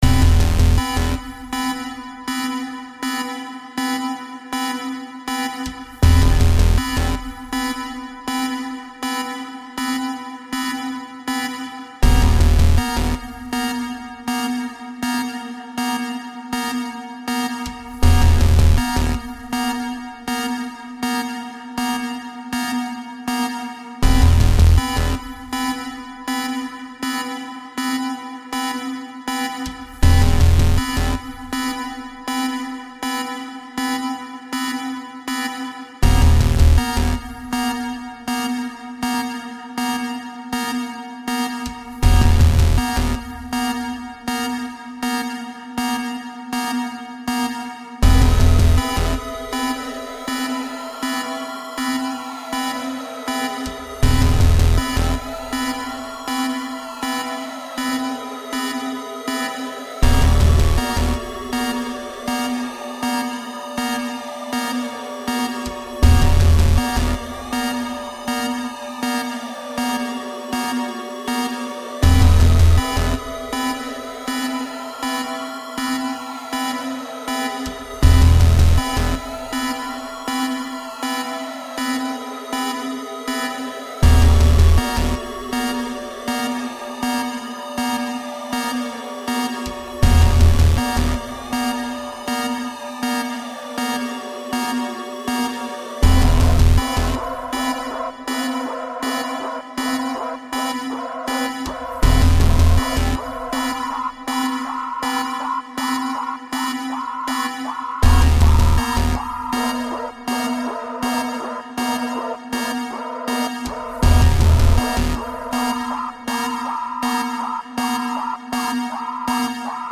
【用途/イメージ】　怪談　都市伝説　ダーク　不気味　不思議　緊張
ベース　シンセ